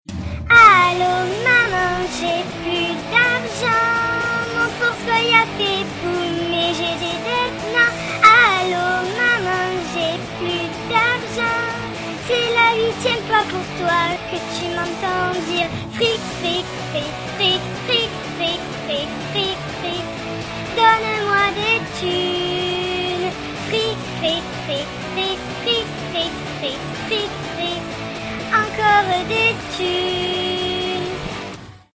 Sonneries de portable, format mp3